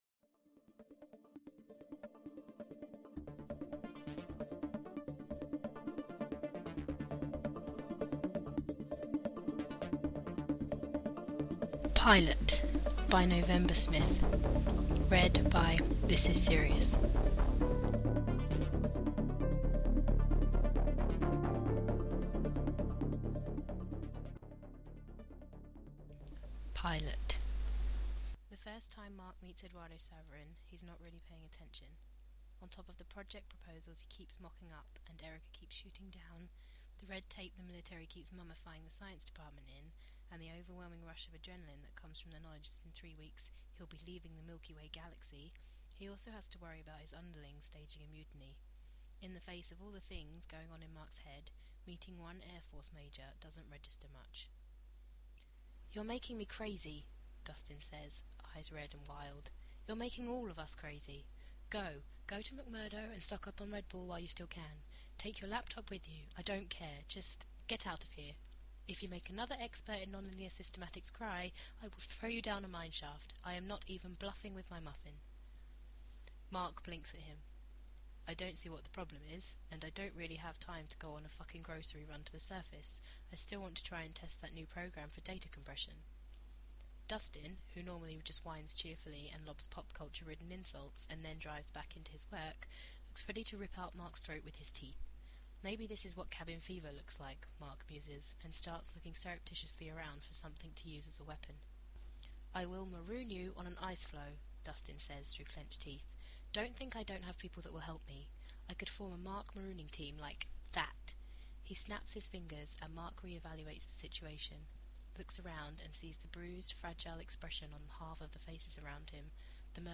Download links for the podfic: MP3 | M4B - 34 MB, 33 min.